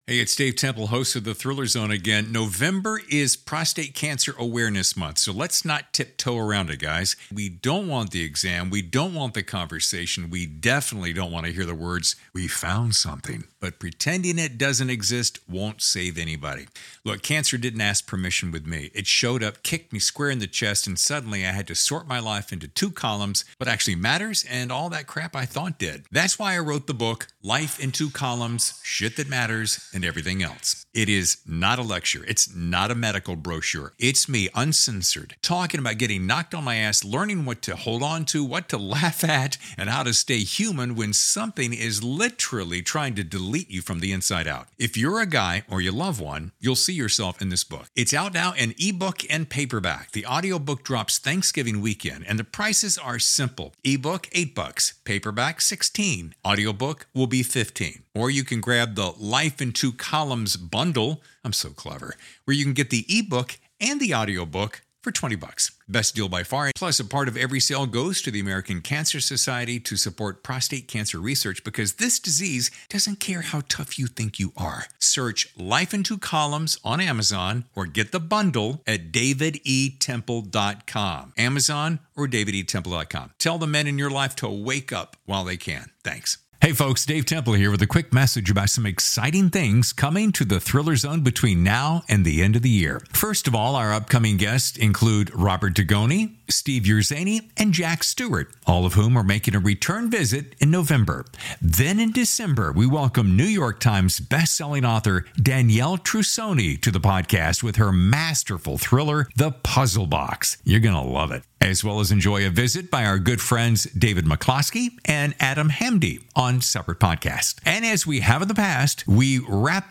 Either way, six of the authors in the Atria Mystery Bus/Simon & Schuster family, have come together for brief conversations, face-to-face, with me to talk about their latest works at the Bouchercon 23 event in San Diego, California.